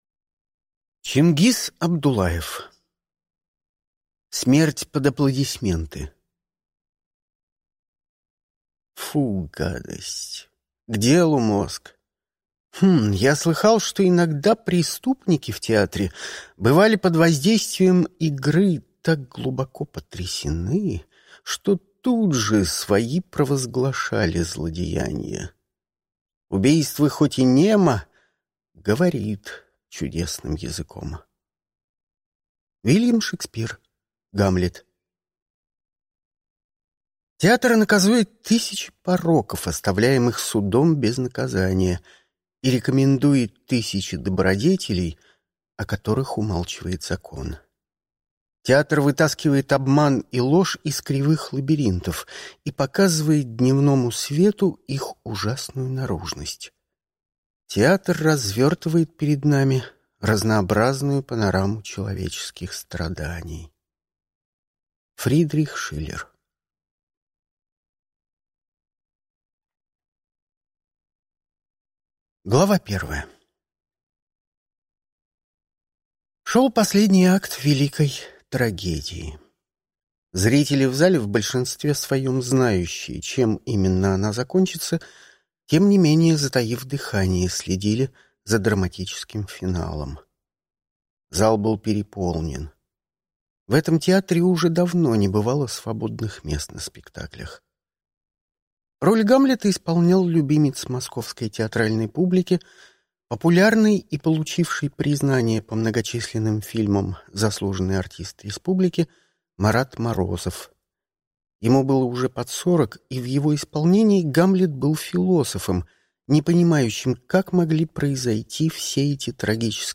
Аудиокнига Смерть под аплодисменты | Библиотека аудиокниг